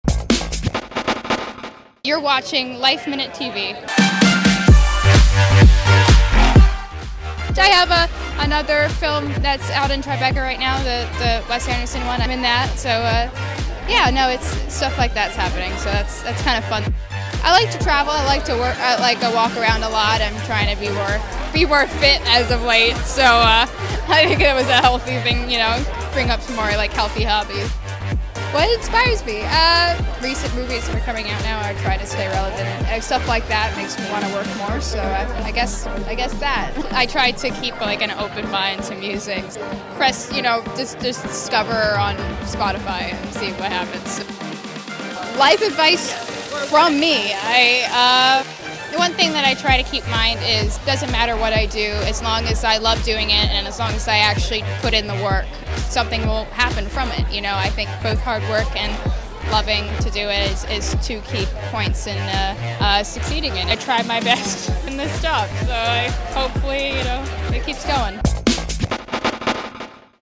We caught up with the busy actress at the Tribeca Film Festival premiere of her latest film, The Adults, and asked her what else she has been up to and likes to do when she’s not working.